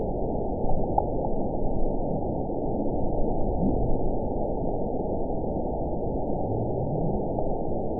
event 917254 date 03/25/23 time 21:01:16 GMT (2 years, 1 month ago) score 9.51 location TSS-AB03 detected by nrw target species NRW annotations +NRW Spectrogram: Frequency (kHz) vs. Time (s) audio not available .wav